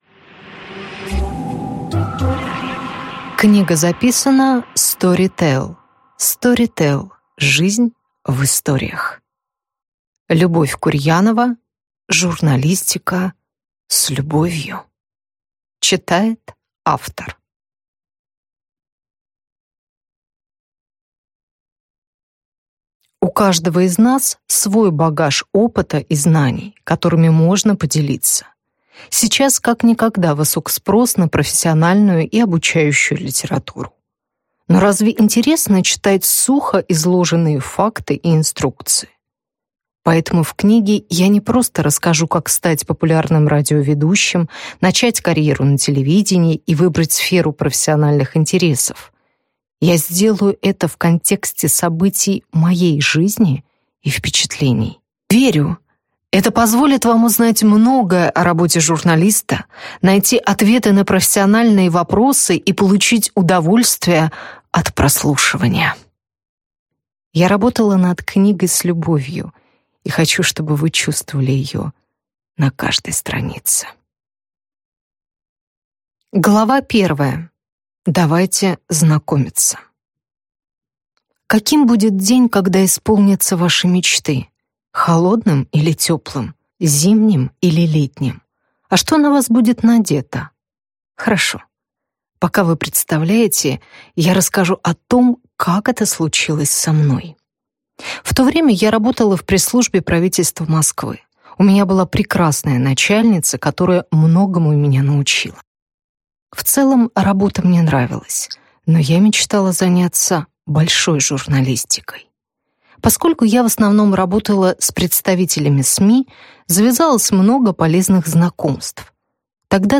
Аудиокнига Журналистика с любовью | Библиотека аудиокниг